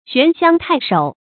玄香太守 xuán xiāng tài shǒu
玄香太守发音